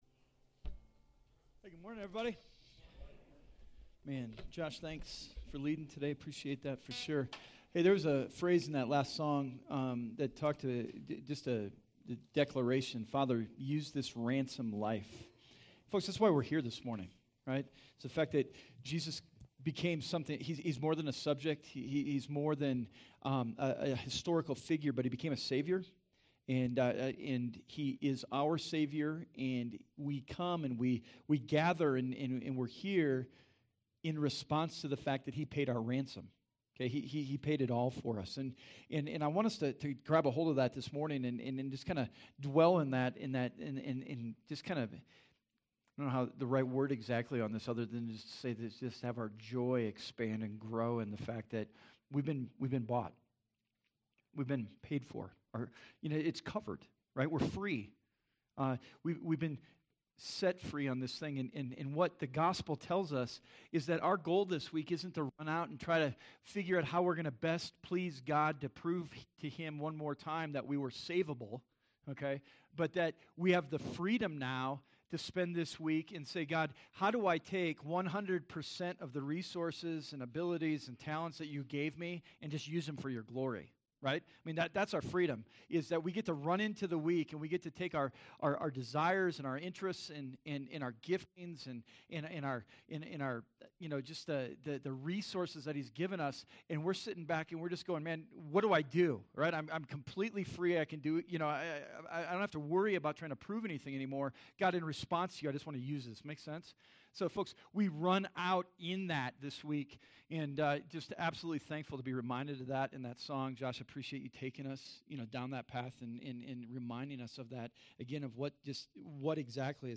2018 You Won’t Be Alone Preacher